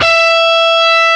DIST GT1-E4.wav